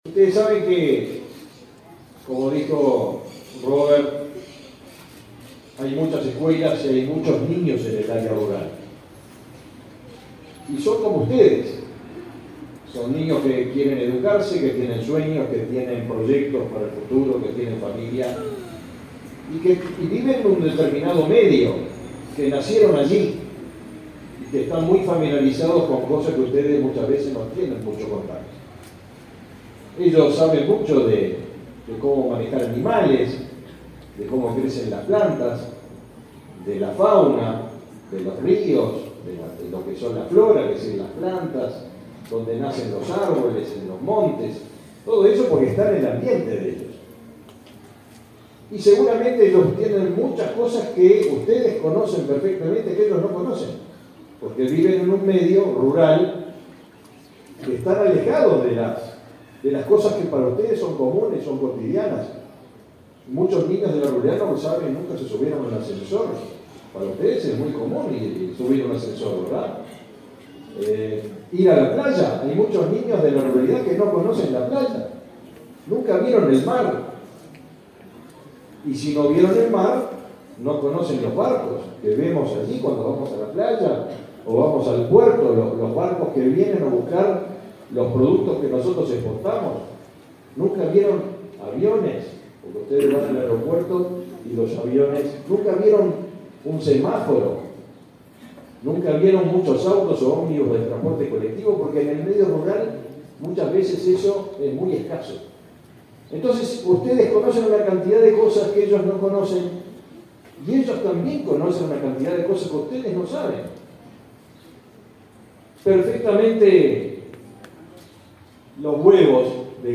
Palabras del ministro Mattos y del presidente del Codicen, Robert Silva
Palabras del ministro Mattos y del presidente del Codicen, Robert Silva 21/07/2022 Compartir Facebook X Copiar enlace WhatsApp LinkedIn El ministro de Ganadería, Fernando Mattos, y el presidente del Codicen, Robert Silva, participaron del lanzamiento del concurso de la ANEP "Aprendemos Ruralidad".